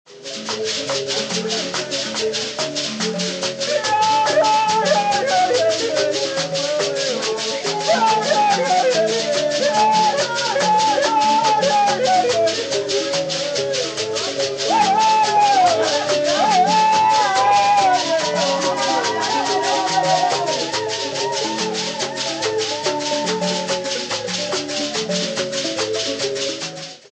mbira group